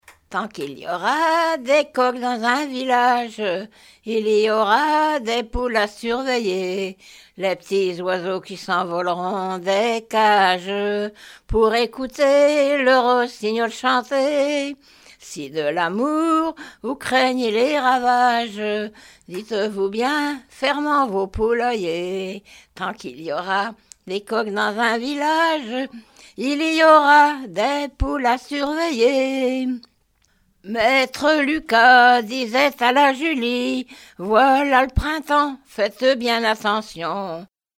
Genre strophique
Pièce musicale inédite